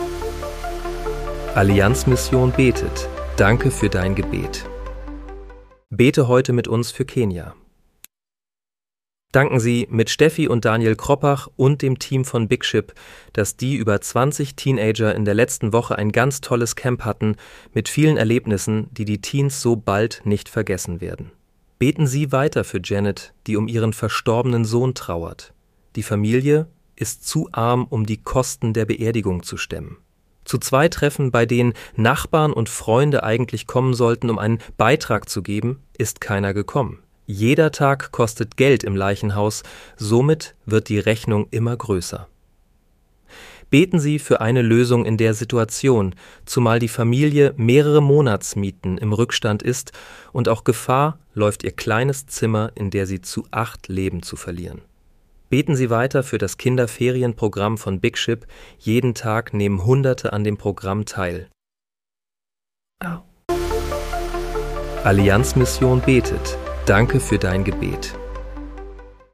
Bete am 23. April 2026 mit uns für Kenia. (KI-generiert mit der